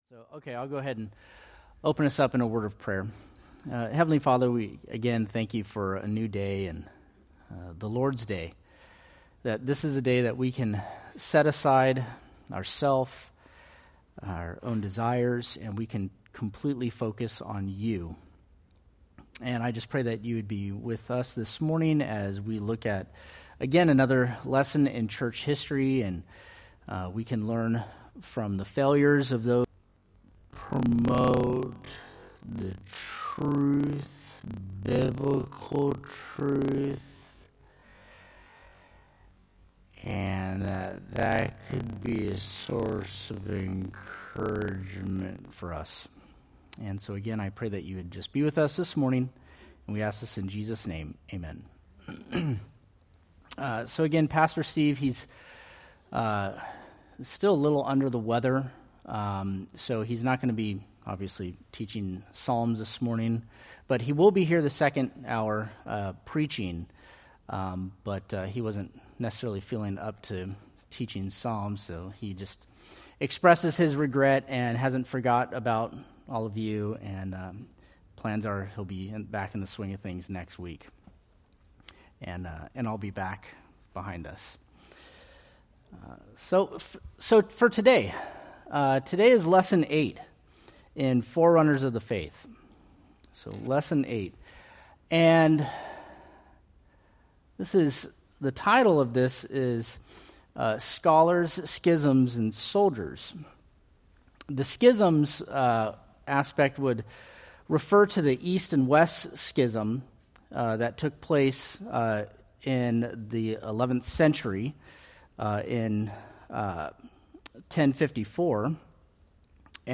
Date: Mar 16, 2025 Series: Forerunners of the Faith Grouping: Sunday School (Adult) More: Download MP3